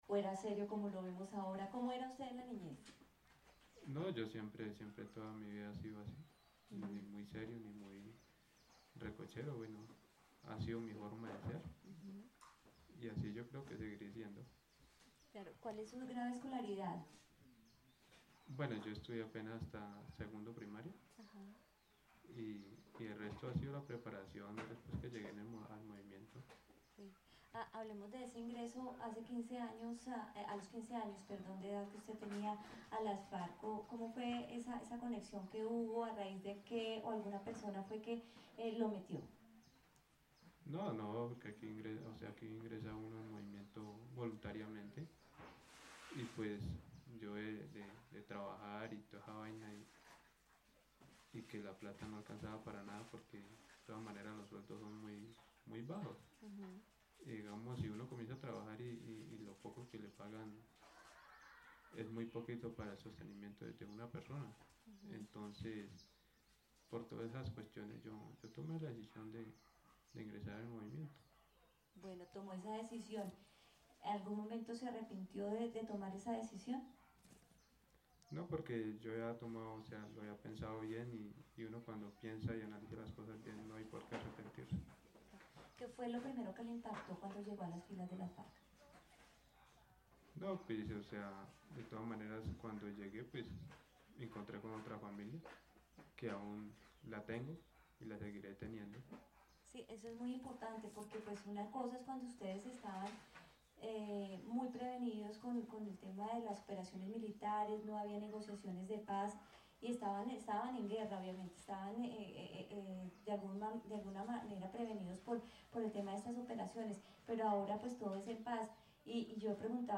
Informe radial